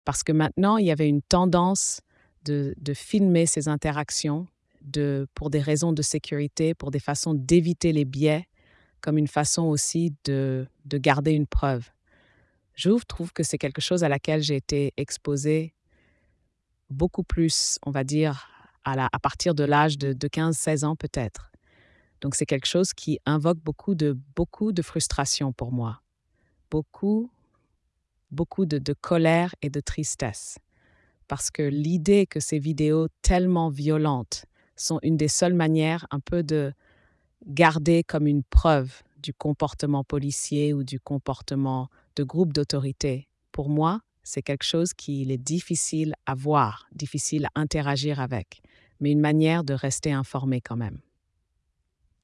Extrait audio d'un entretien de recherche avec voix modifiée pour conserver l'anonymat.
Ottawa